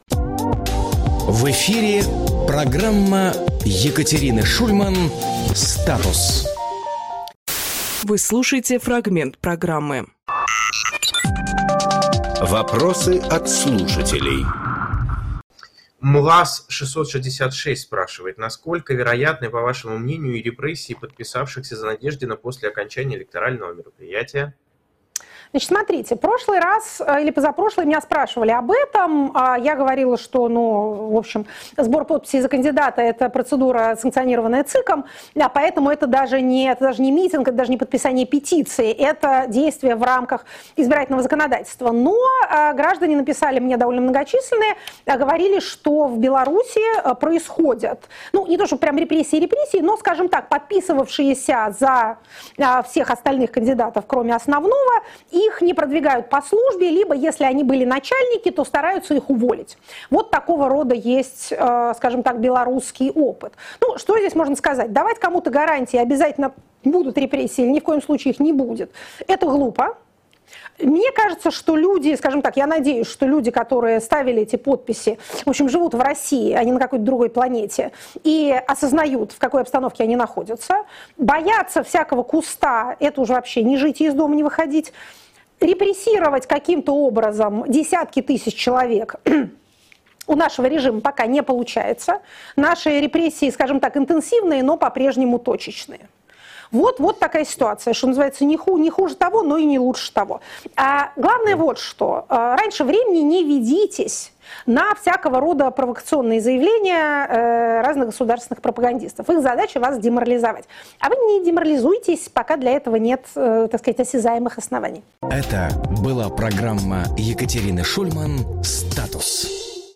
Екатерина Шульманполитолог
Фрагмент эфира от 13.02